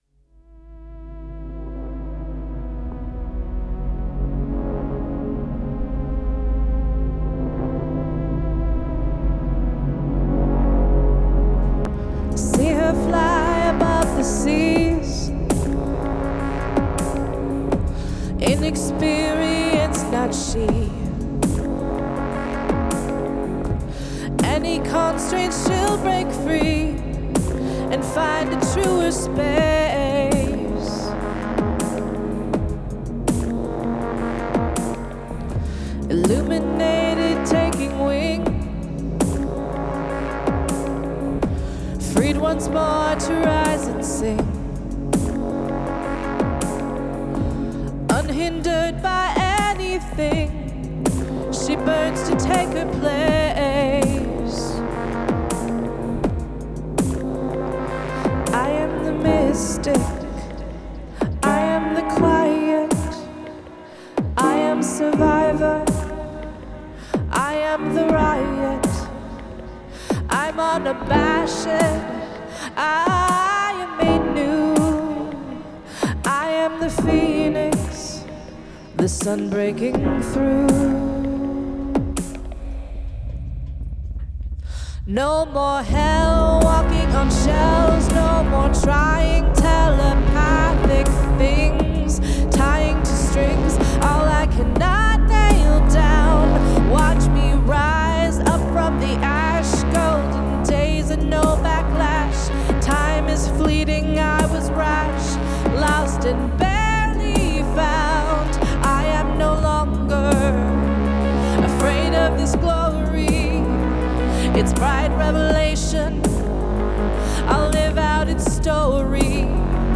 synths